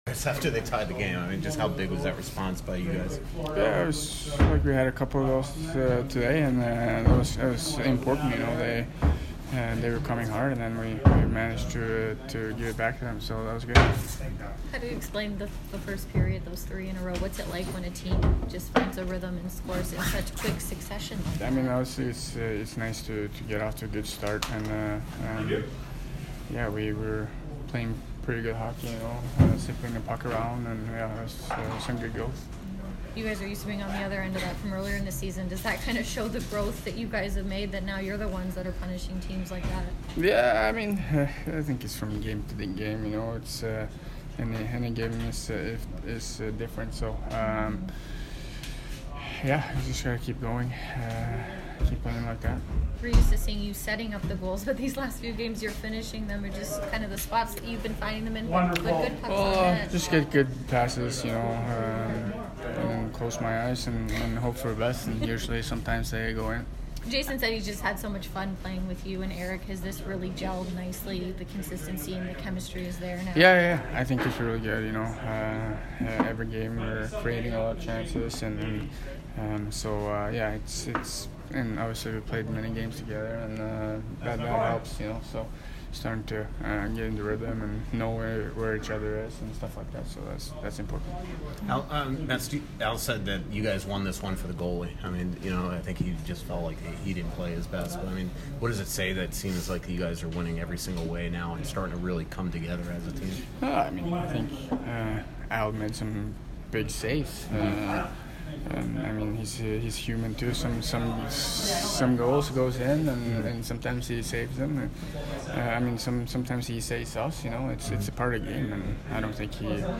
Zuccarello post-game 12/5